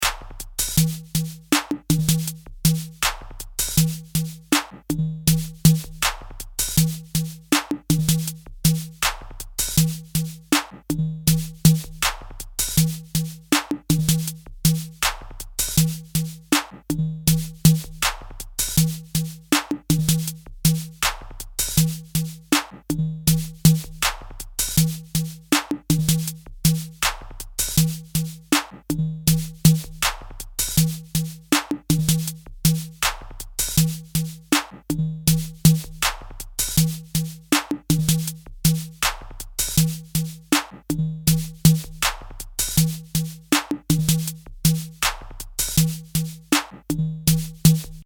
Bucle de percusión electrónica
Música electrónica
percusión
repetitivo
rítmico
sintetizador